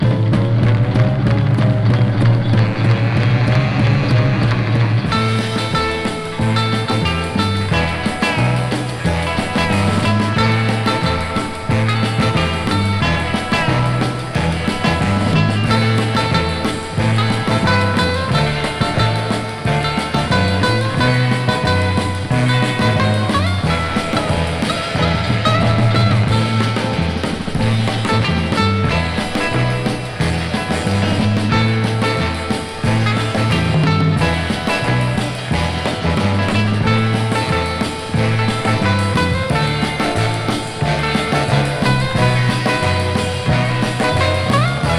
Surf, Rock & Roll　USA　12inchレコード　33rpm　Stereo